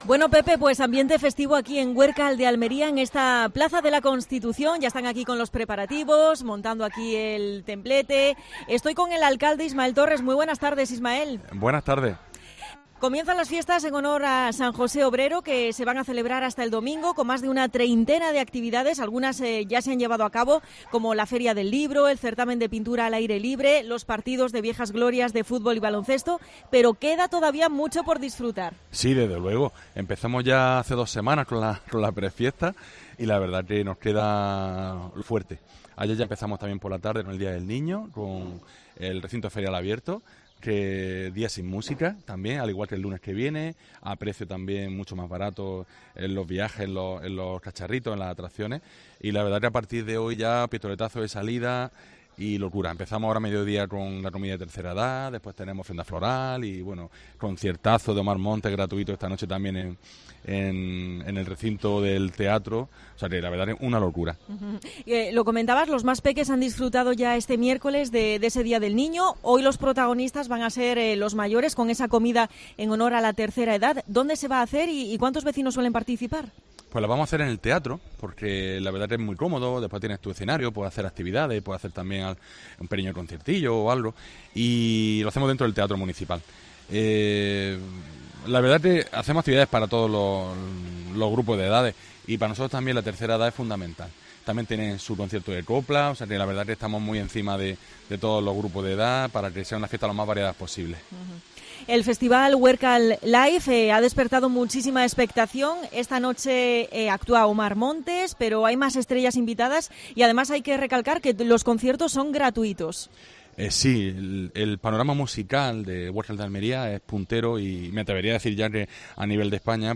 AUDIO: Fiestas patronales en Huércal de Almería. Entrevista al alcalde de la localidad (Ismael Torres).